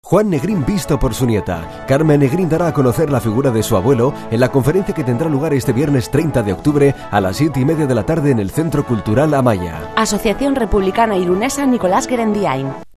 Cuñas en "Radio Irun" anunciando la Conferencia